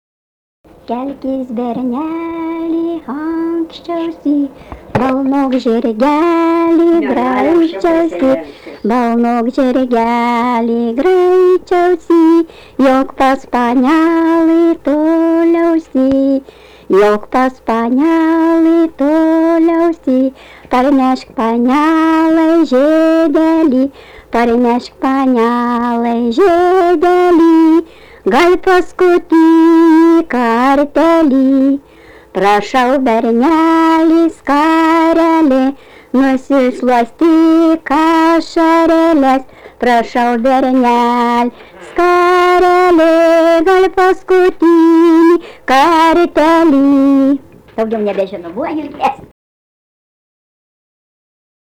Dalykas, tema daina
Erdvinė aprėptis Baibokai
Atlikimo pubūdis vokalinis